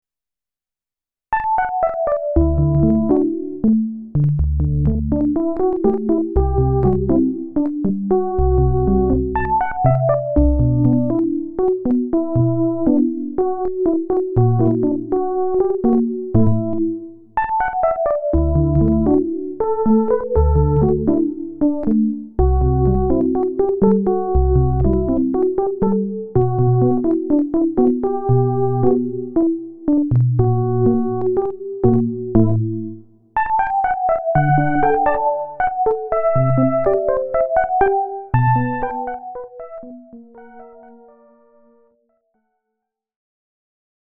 Everything was played right on the AstroLab, and there was no extra sound processing done.
Here’s a set of quirky lo-fi keys dubbed Autumn Keys. No, the crunchy noise heard here isn’t a flaw. It’s intentionally a part of the preset.